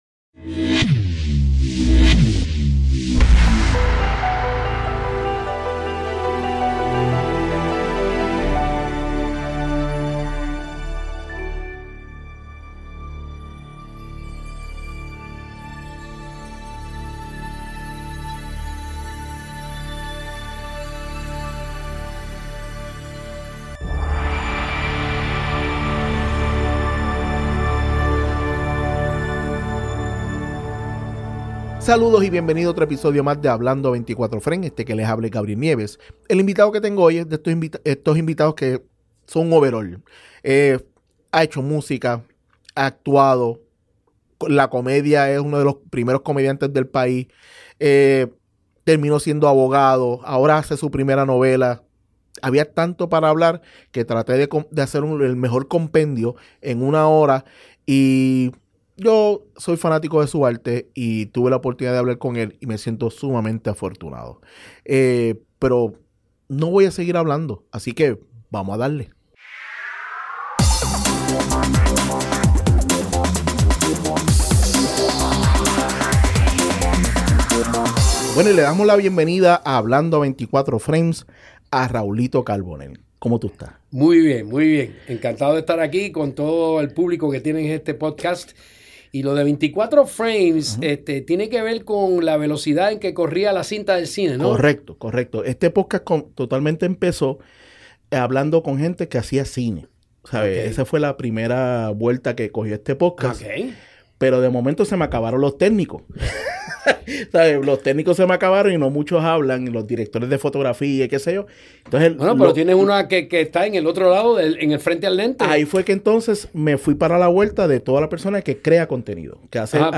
En este episodio de Hablando a 24 Frames tuve el placer de sentarme a dialogar con un actor que admiro mucho por lo diverso que es como artista.
HA24F es grabado desde los estudios de GW-Cinco